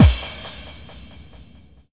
VEC3 FX Reverbkicks 21.wav